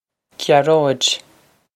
Gearóid Gya-rohj
Pronunciation for how to say
This is an approximate phonetic pronunciation of the phrase.